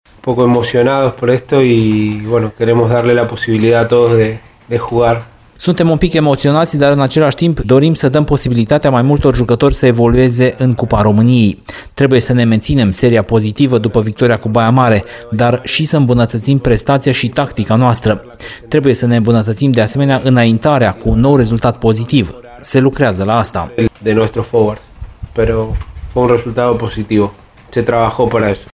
Intențiile staff-ului tehnic au fost făcute publice cu ocazia conferinței de presă dinaintea jocului de sâmbătă, ora 11, cu Tomitanii Constanța, de pe stadionul ”Dan Păltinișanu”.